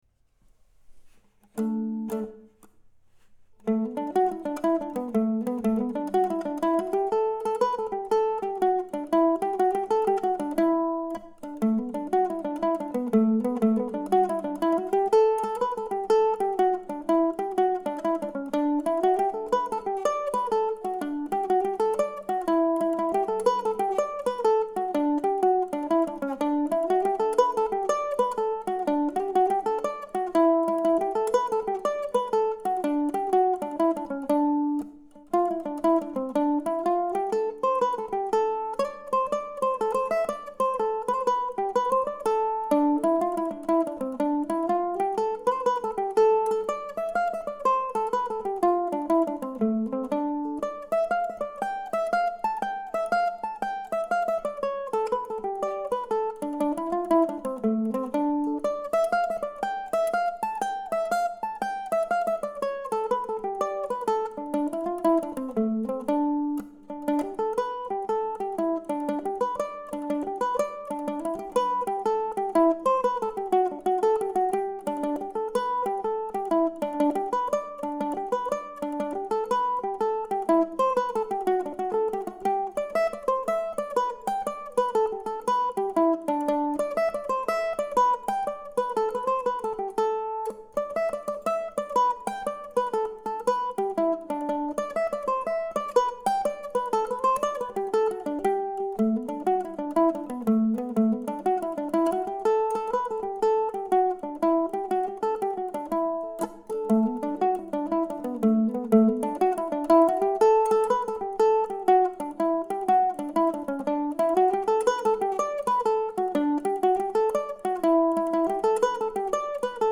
This is primarily a way for me to document at least some of the music that I write in a simple, demo-style format.
If you've sampled more than a few of these tunes you can hear that they are mostly bare bones recordings.
Today's recordings are as bare as it gets, one mandolin playing simple melody lines. The only slight difference here is that, instead of playing each tune twice in a row, I play each tune only once and then go back and play them each once again.